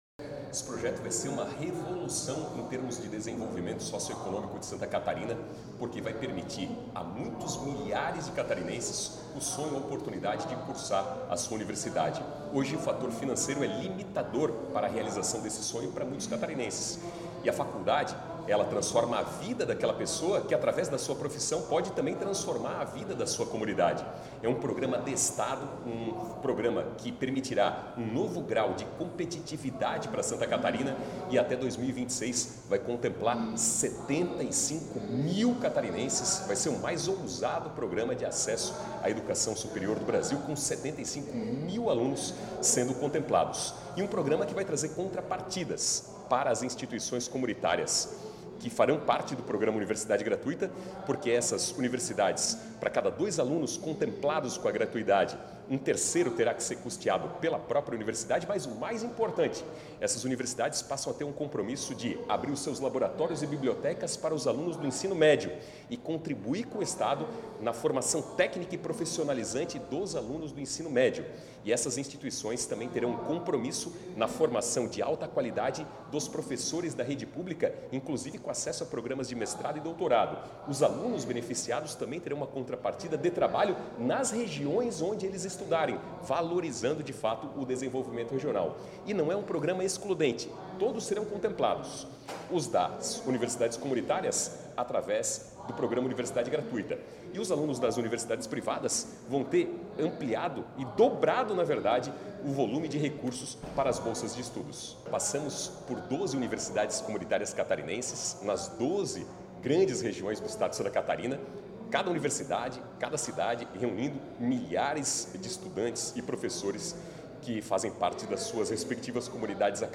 Na sessão desta quinta-feira, dia 4, o deputado estadual Napoleão Bernardes (PSD) deu os primeiros detalhes sobre o Programa Universidade Gratuita.
deputado-estadual-Napoleao-Bernardes-PSD.mp3